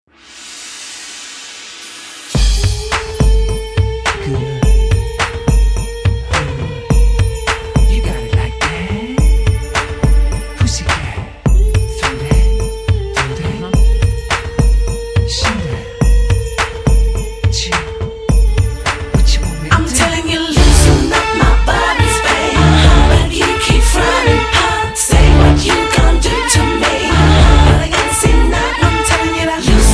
karaoke, mp3 backing tracks
rap, r and b, hip hop